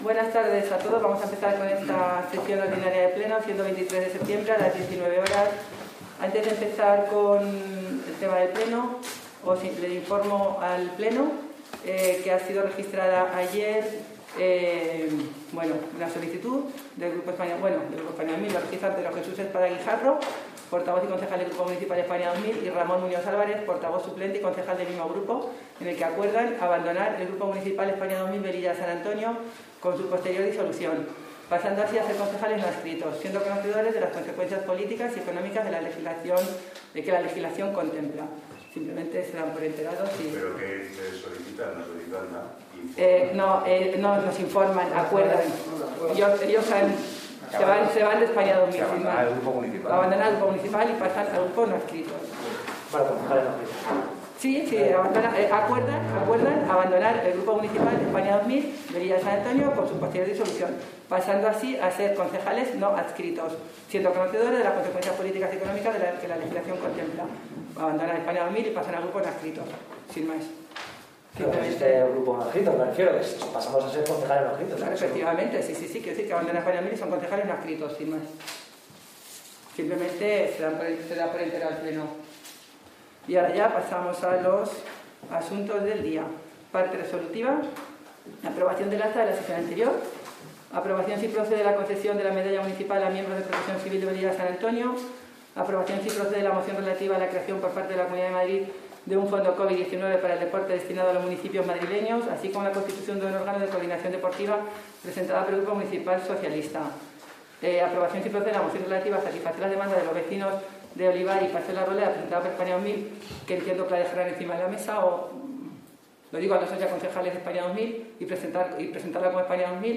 Pleno extraordinario de 23 de diciembre de 2020.